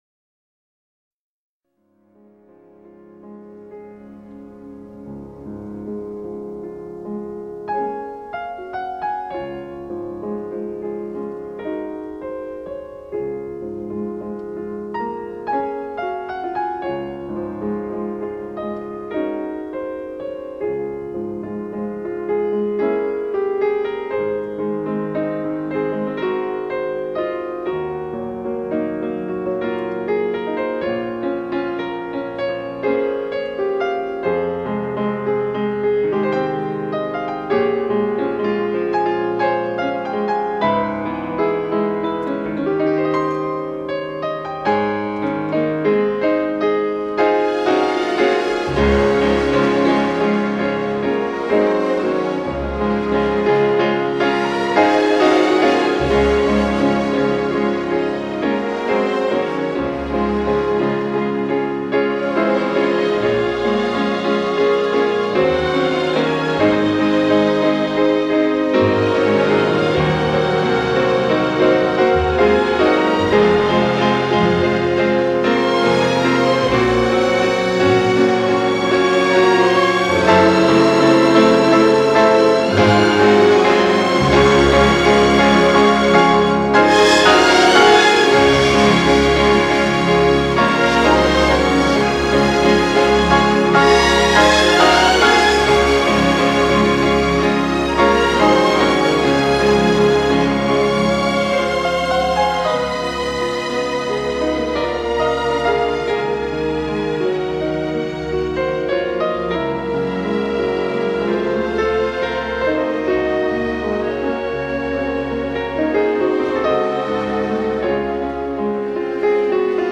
如歌的行板